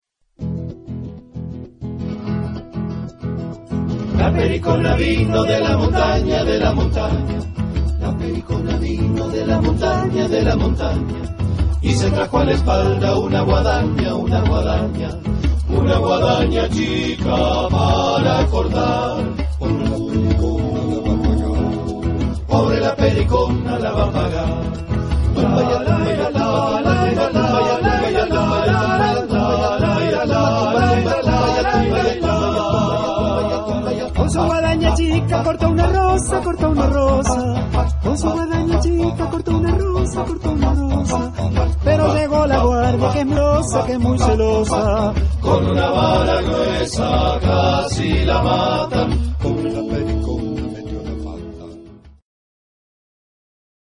Genre-Style-Form: Secular ; Latin american ; Partsong
Type of Choir: TTBarBarB  (5 men voices )
Tonality: E major